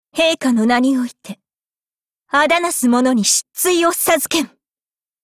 贡献 ） 协议：Copyright，人物： 碧蓝航线:怨仇语音 您不可以覆盖此文件。
Cv-20707_battlewarcry.mp3